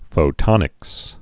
(fō-tŏnĭks)